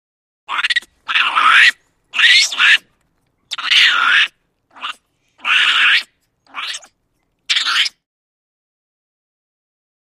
Monkey ( Unknown ) Screams, Chatter. Series Of Angry Screams And Chitters. Close Perspective.